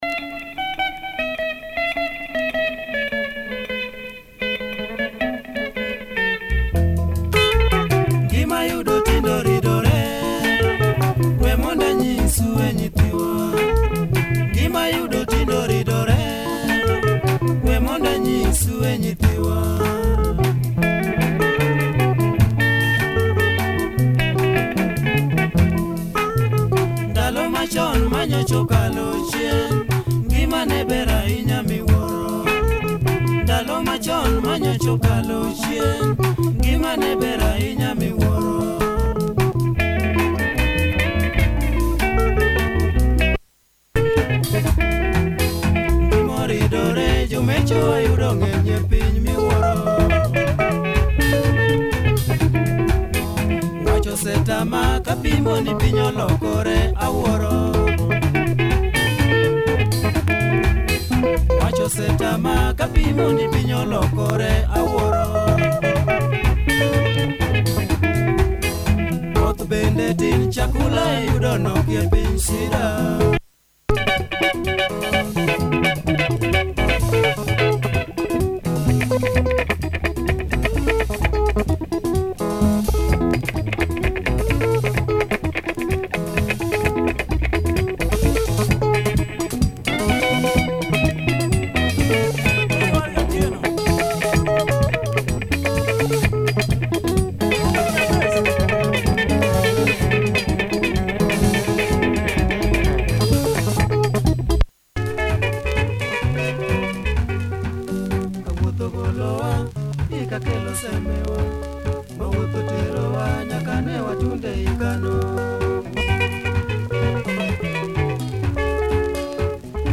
Good punchy early LUO benga from 1974, spacey breakdown!